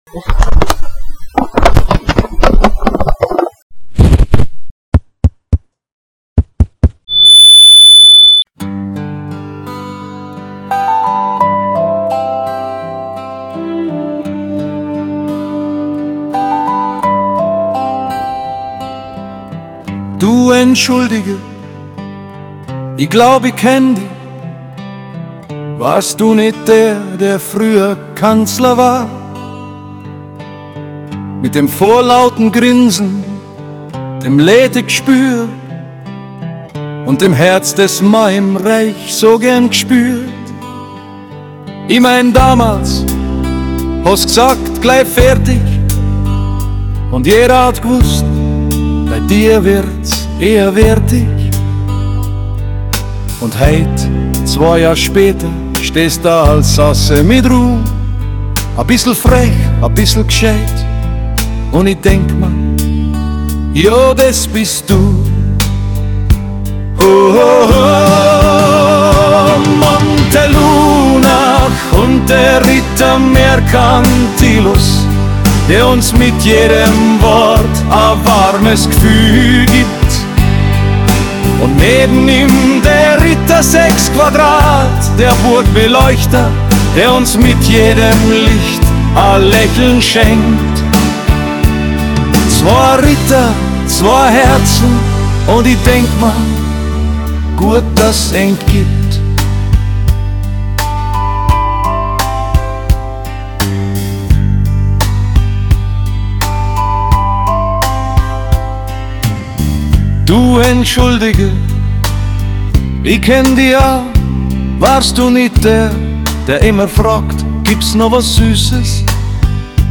musikalische Fechsung anlässlich der Ursippenfeyer